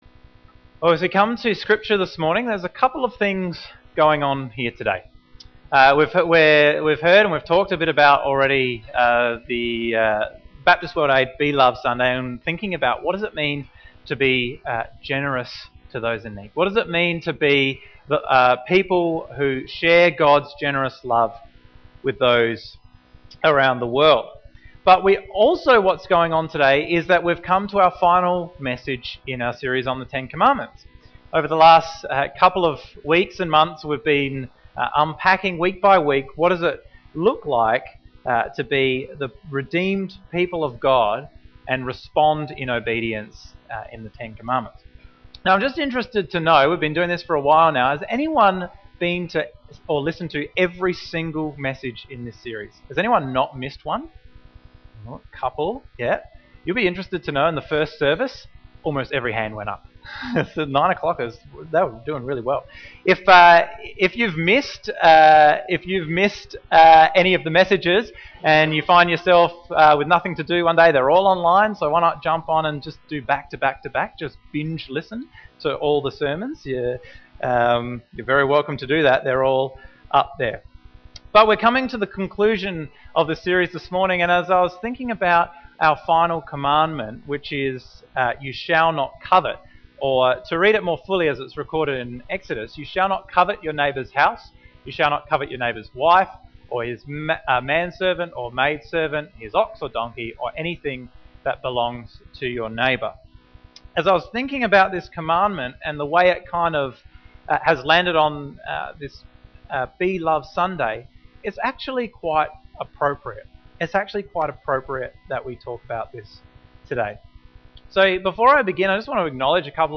This message on coveting was fittingly within the context of Baptist World Aid’s ‘Be Love Sunday’. To covet is to desire obsessively, to wish to acquire something or someone for yourself.